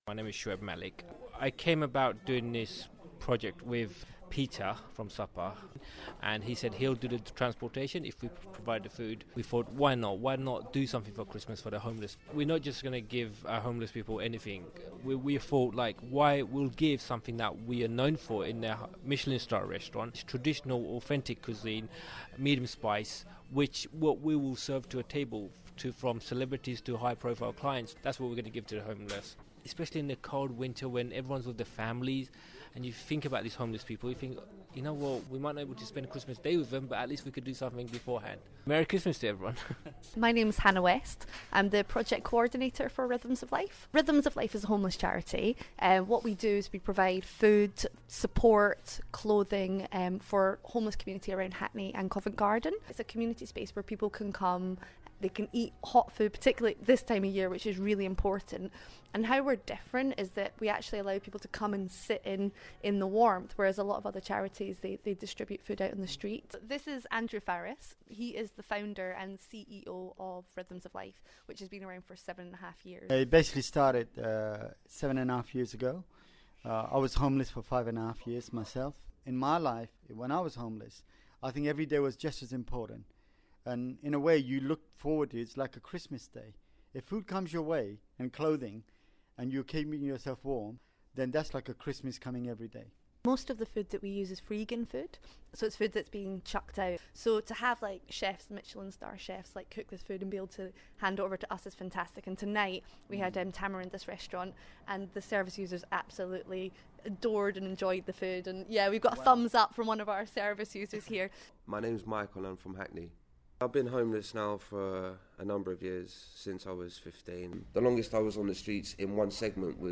BBC Radio London Homeless package